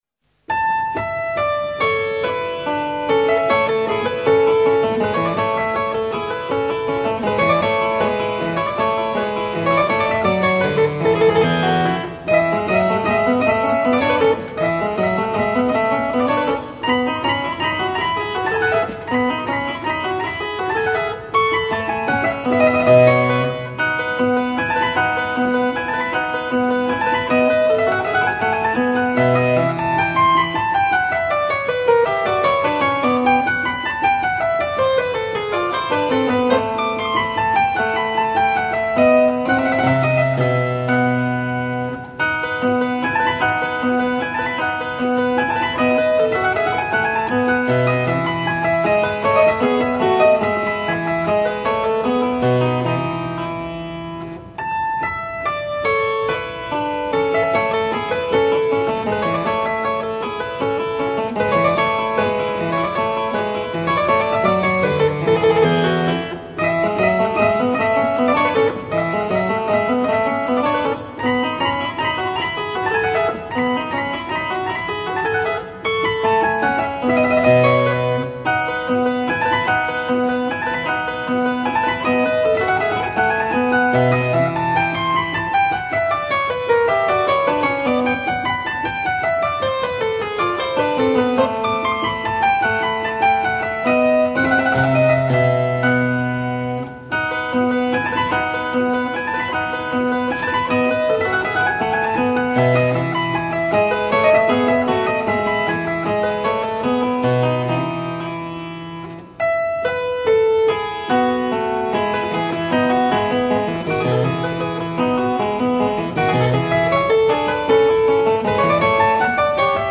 Sonate pour clavecin Kk 300 : Allegro